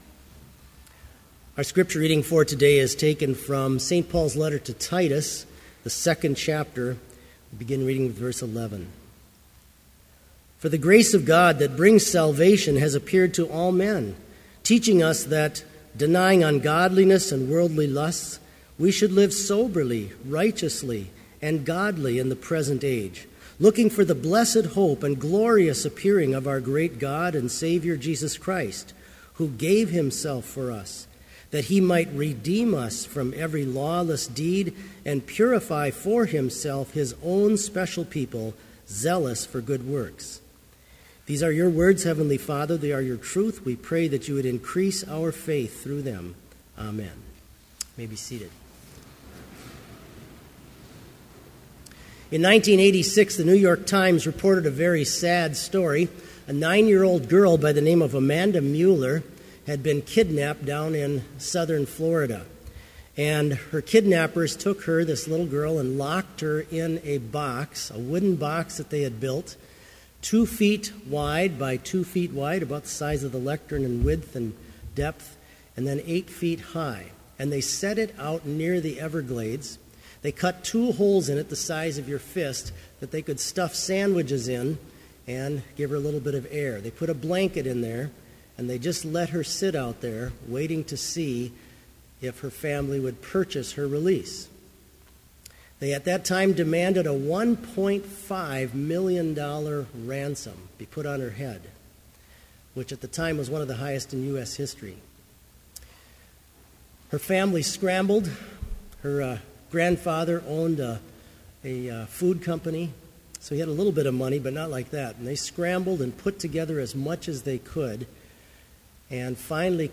Complete Service
• Hymn 92, vv. 1-3, Lift Up Your Heads
• Homily
This Chapel Service was held in Trinity Chapel at Bethany Lutheran College on Tuesday, December 9, 2014, at 10 a.m. Page and hymn numbers are from the Evangelical Lutheran Hymnary.